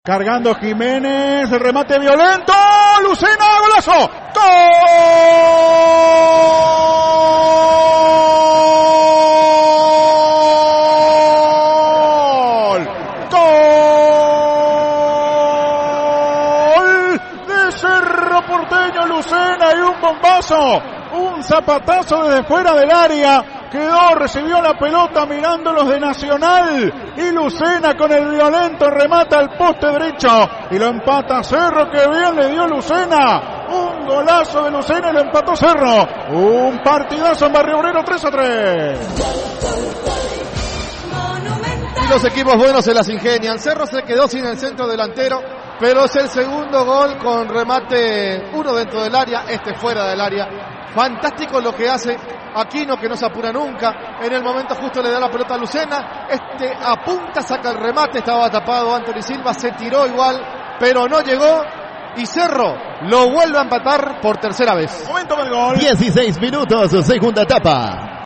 Relatos y comentarios del equipo de Fútbol a lo Grande.